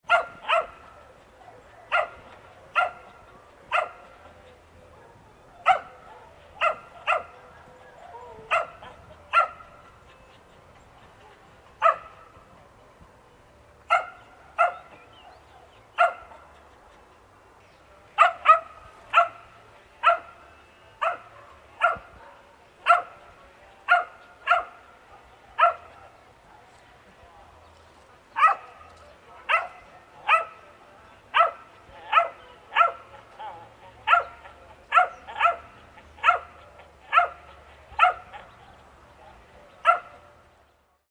Dachshund Barking Constantly Sound Effect
This realistic sound effect captures a neighbor’s dachshund barking constantly. The small dog produces sharp, high-pitched barks and keeps barking nonstop, creating a true neighborhood atmosphere.
Dachshund-barking-constantly-sound-effect.mp3